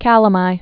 (kălə-mī)